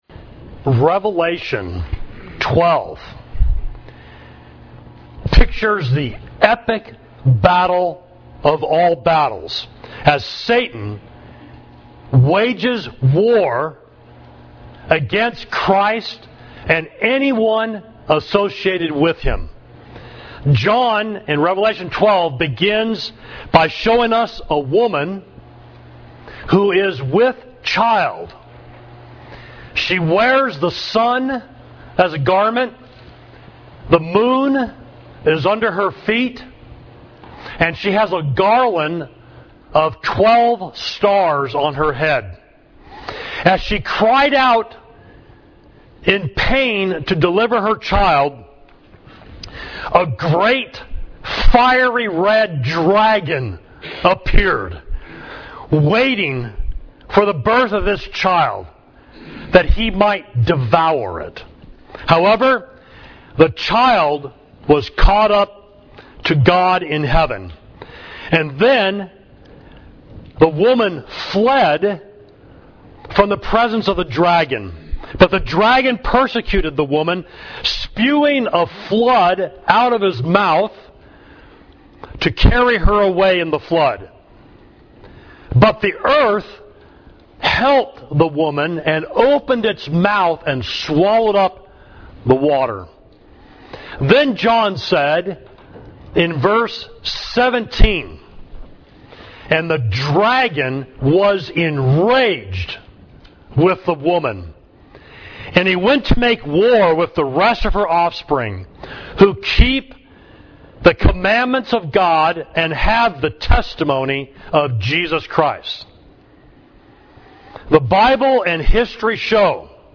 Sermon: The Dragon Is Enraged against the Woman, Revelation 12